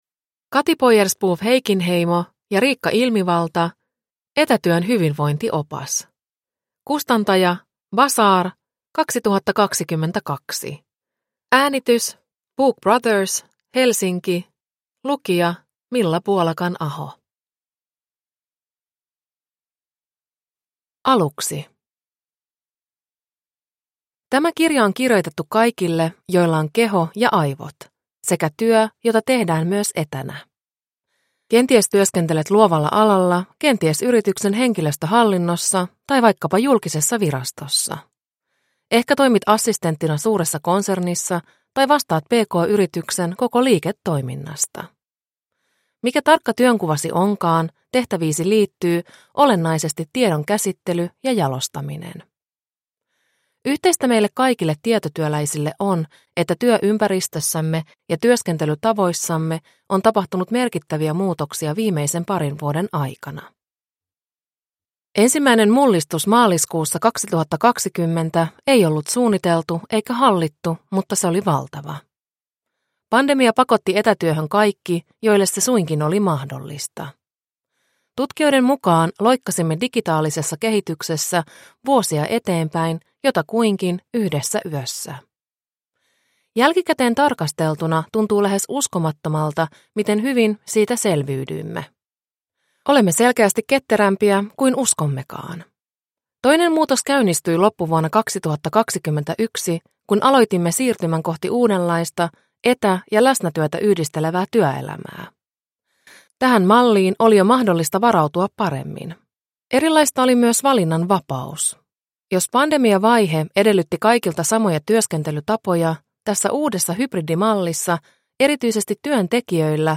Etätyön hyvinvointiopas – Ljudbok – Laddas ner